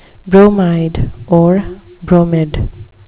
bromide (BRO-myd) noun
Pronunciation: